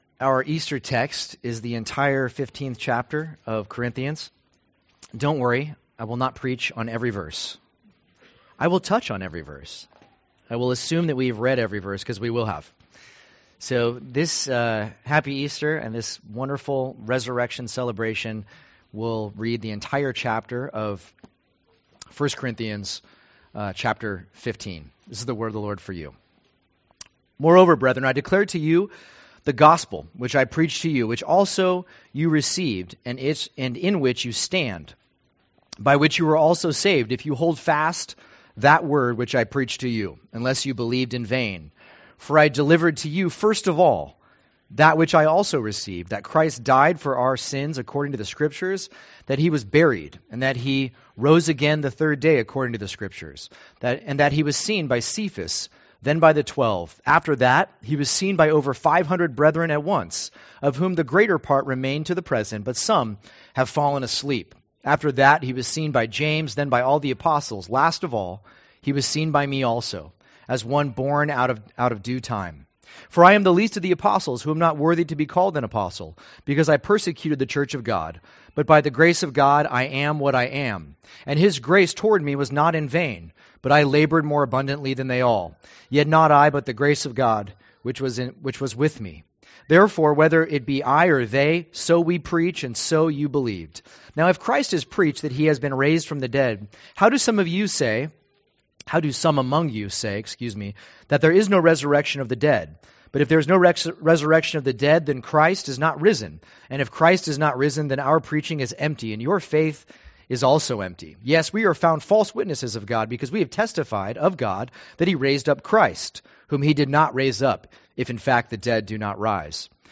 2023 The Most Important Thing Preacher